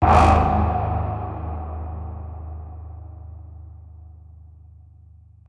TM-88 Hit #07.wav